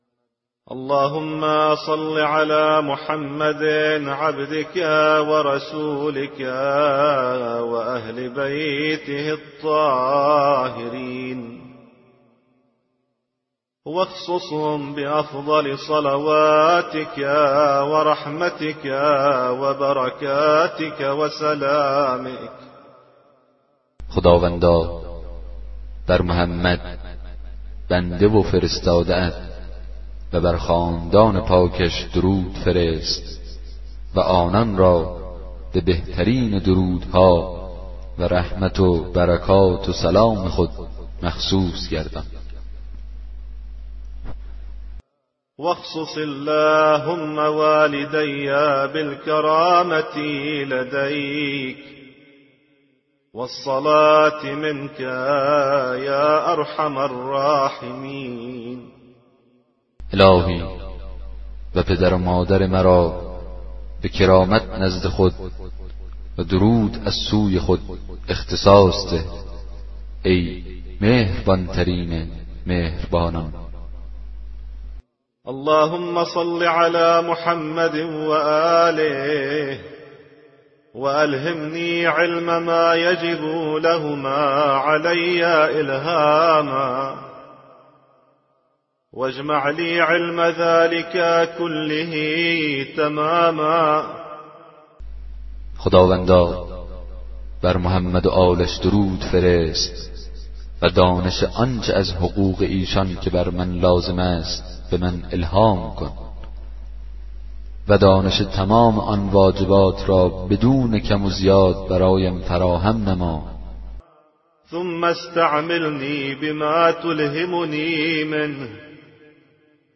کتاب صوتی دعای 24 صحیفه سجادیه